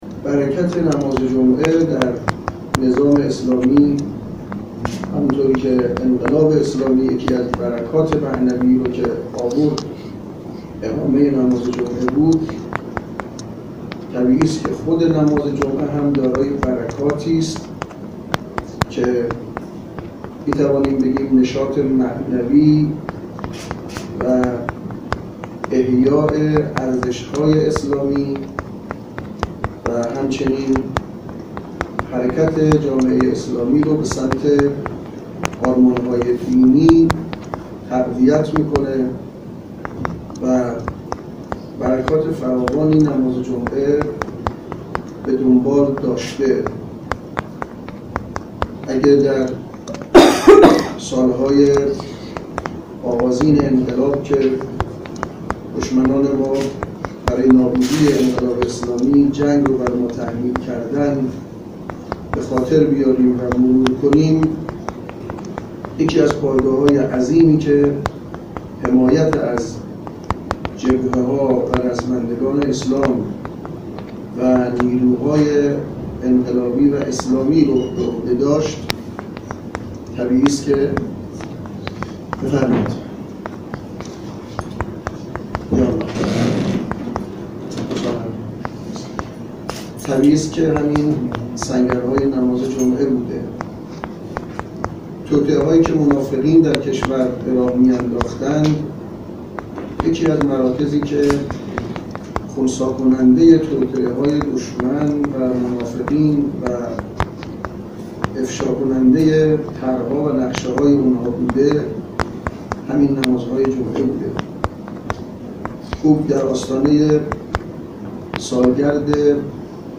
به گزارش خبرنگار خبرگزاری رسا، حجت الاسلام عبدالعلی ابراهیمی امام جمعه موقت شهرری، امروز در نشست خبری که با اصحاب رسانه به مناسبت فرارسیدن چهلمین سالروز اولین اقامه نماز جمعه در دفتر امام جمعه شهرری برگزار شد، نماز جمعه را از برکات انقلاب اسلامی برشمرد و گفت: اتحاد، امنیت و آرامش از برکات نماز جمعه به شمار می رود.